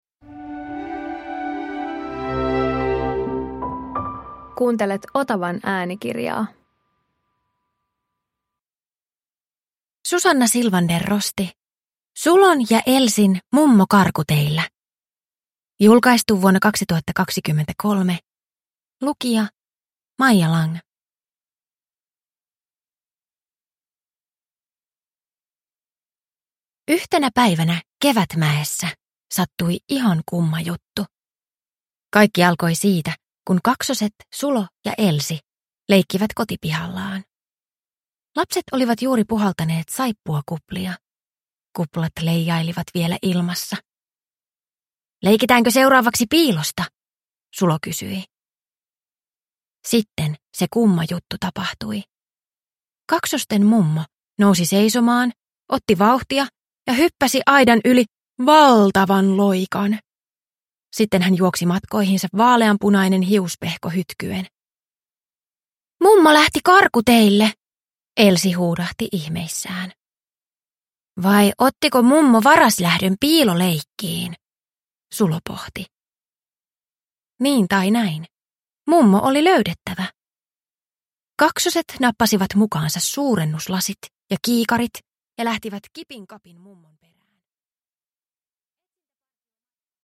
Sulon ja Elsin mummo karkuteillä – Ljudbok – Laddas ner